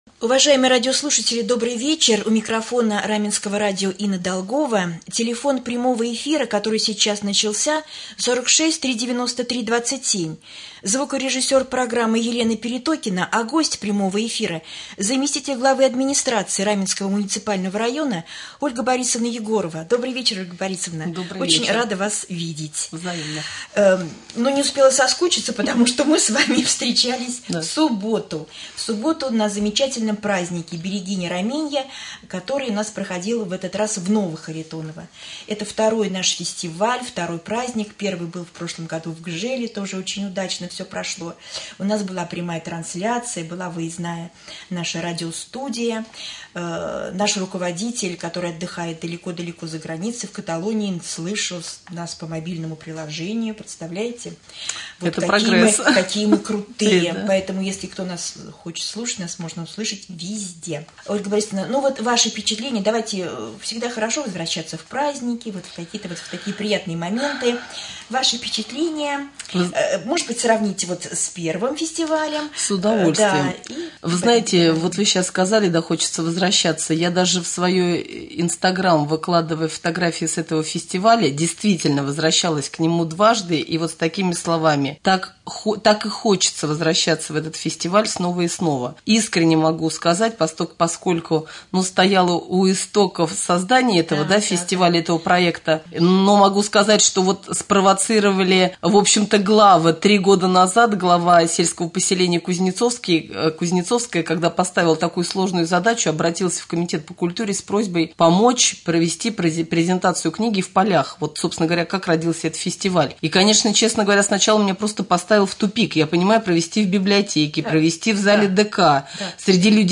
Прямой эфир. Гость студии заместитель главы администрации Раменского района О.Б.Егорова.